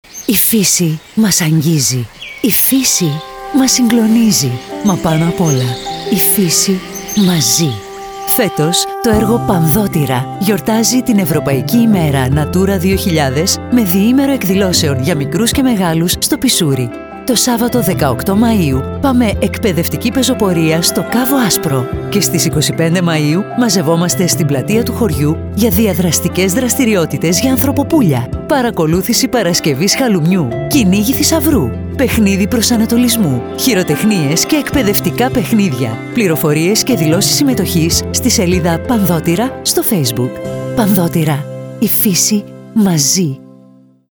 TV spots and Radio spots broadcasted
10th Radio spot: Natura 2000 – Website